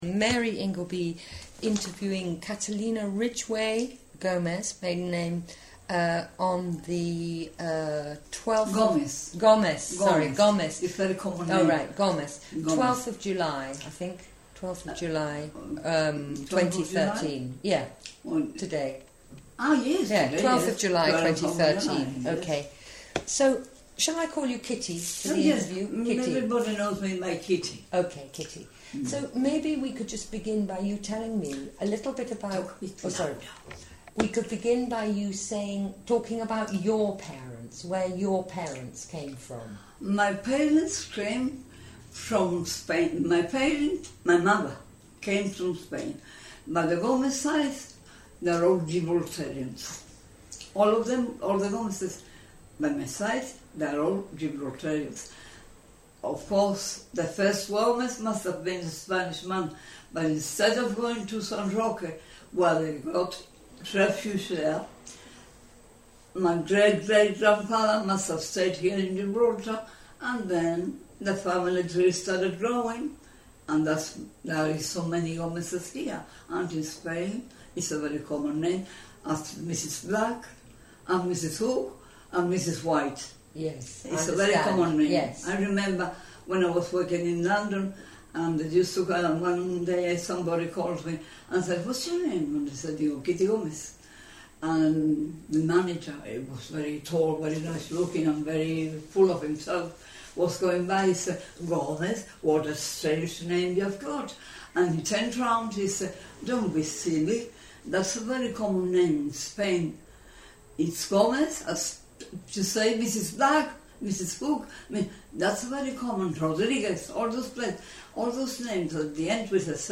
This is a vivid interview covering family life in 1930s Gibraltar; culture; evacuation to London during the Blitz; when the family arrived in London they lived for nearly a year in The Empress Hall, an ice skating rink in Fulham where they bedded down between the seats.